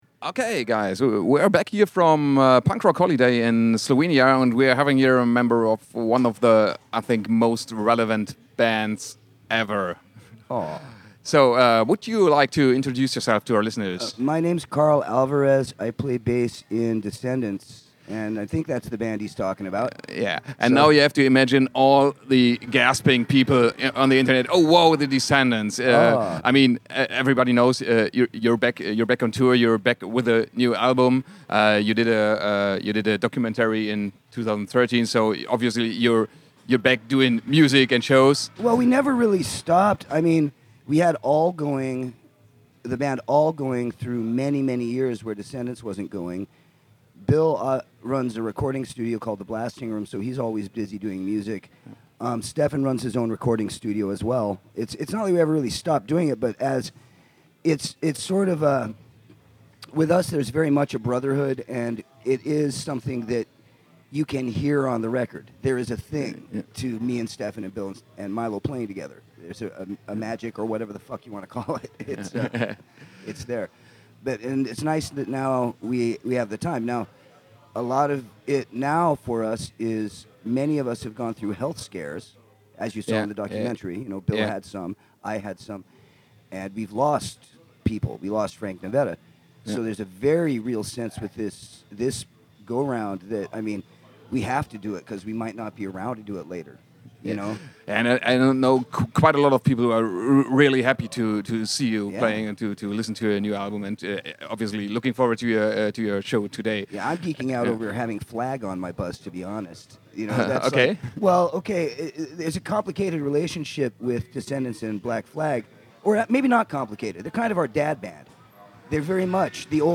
interview-with-descendents-at-punk-rock-holiday-1-6-mmp.mp3